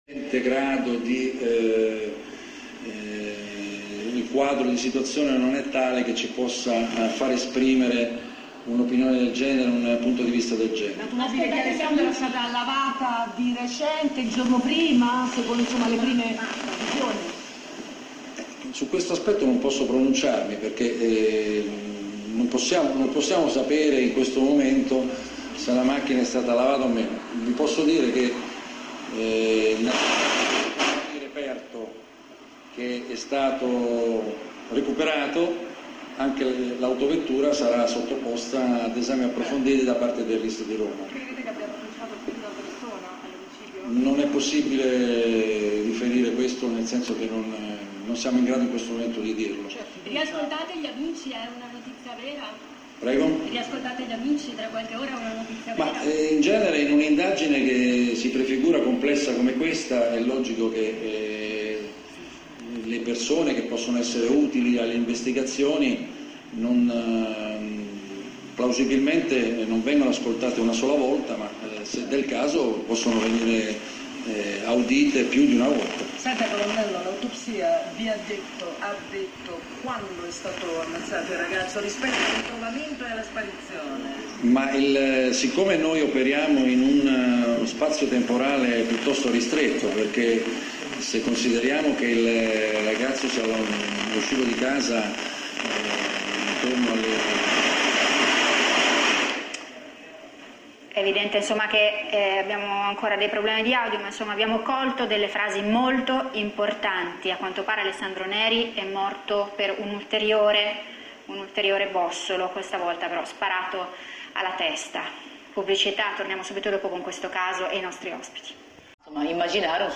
Oggi pomeriggio, il caso è stato attenzionato alla trasmissione “La Vita in Diretta”, nello spazio curato da Francesca Fialdini, che ha ospitato la criminologa dottoressa Roberta Bruzzone. I loro interventi, possono essere ascoltati nella registrazione che segue.